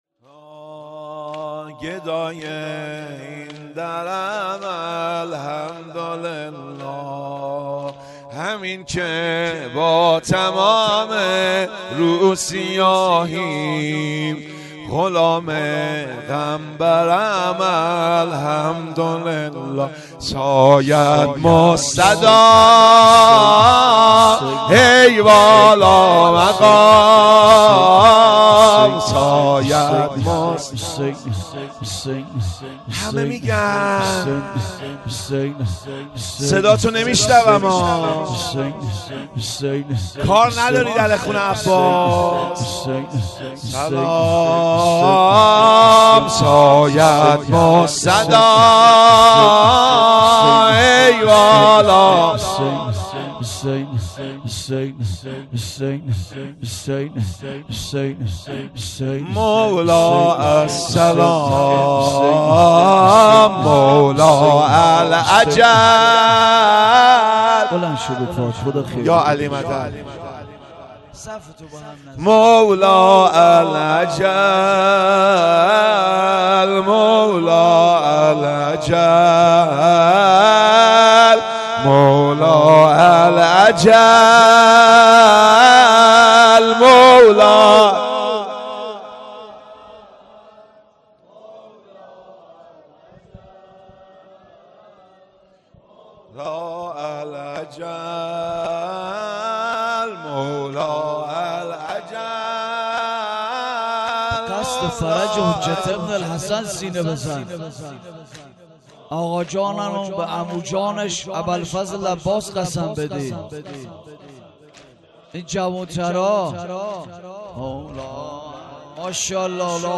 شب نهم دهه اول محرم ۱۴۰۳